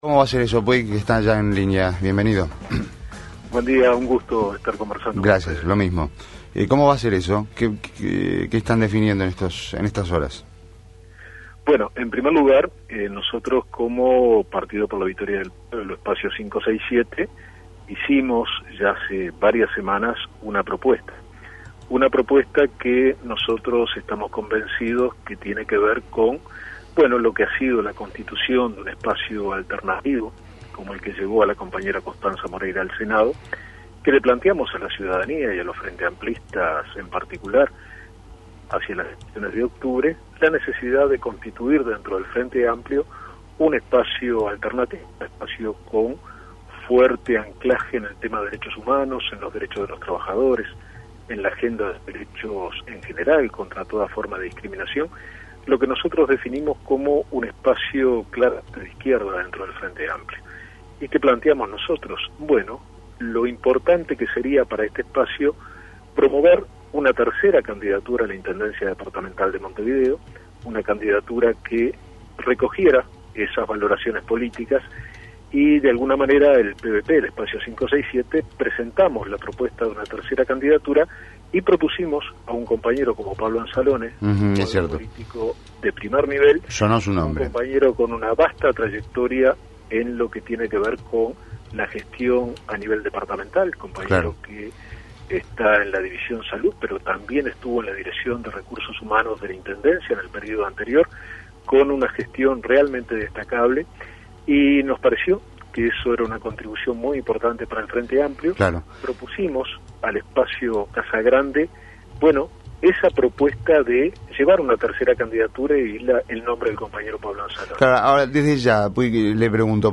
Entrevista a Luis Puig en Rompkbezas